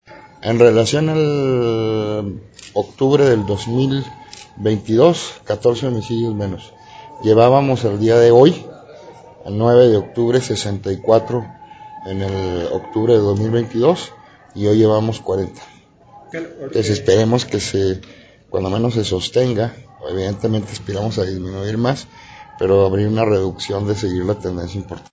AUDIO: CÉSAR JÁUREGUI MORENO, FISCALÍA GENERAL DEL ESTADO